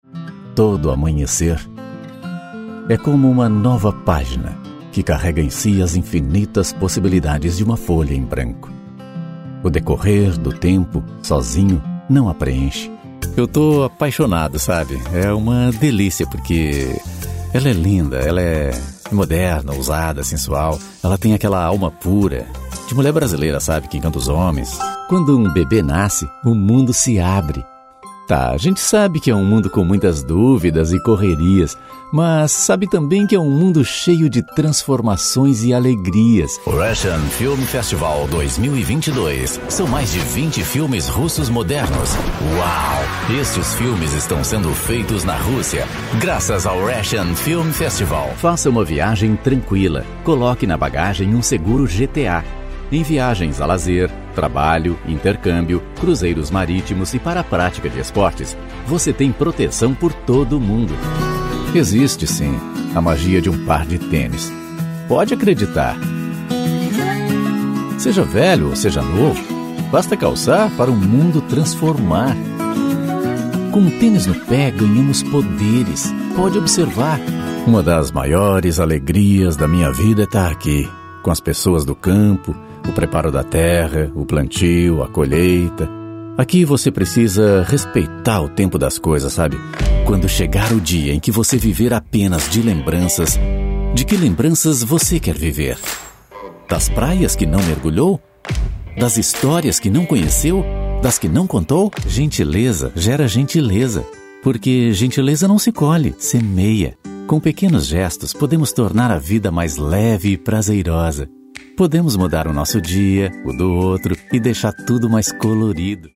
Materno
Natural
Adulto